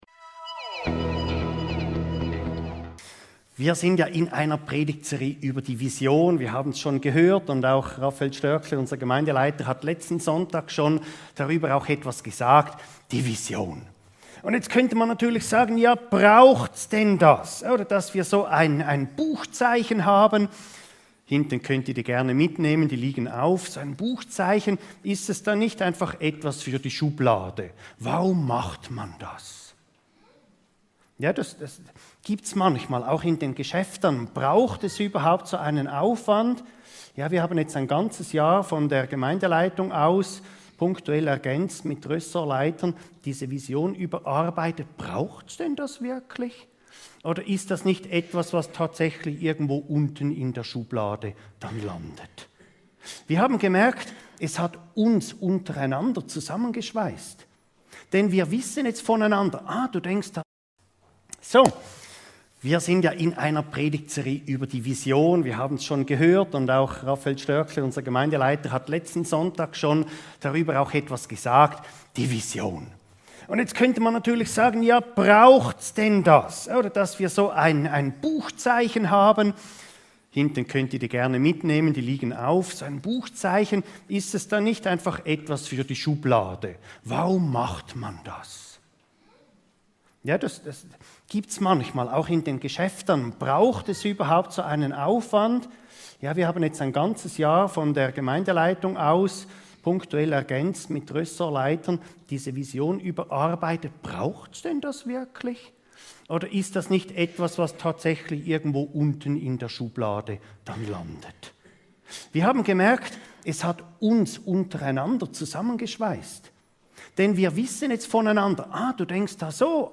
Kennzeichen einer Gemeindevision ~ Your Weekly Bible Study (Predigten) Podcast